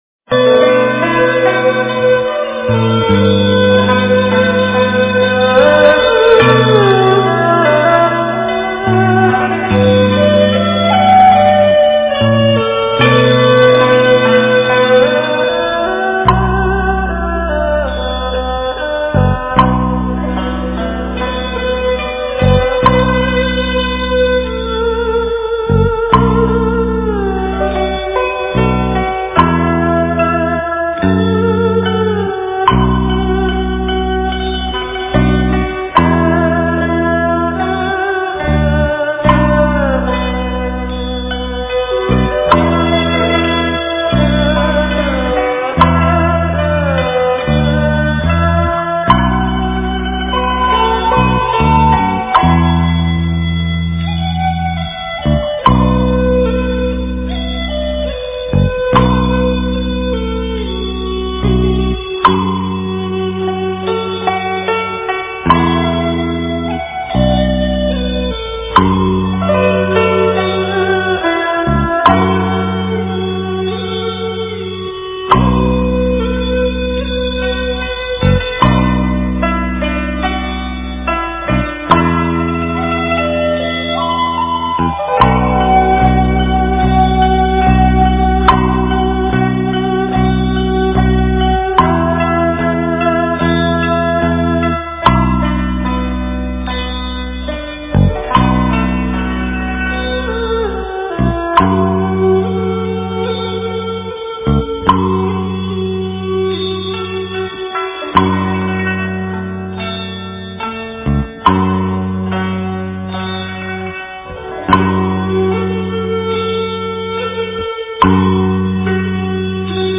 般若波罗蜜多心经 诵经 般若波罗蜜多心经--观音妙韵 点我： 标签: 佛音 诵经 佛教音乐 返回列表 上一篇： 大悲咒 下一篇： 心经 相关文章 职场30身和同住--佛音大家唱 职场30身和同住--佛音大家唱...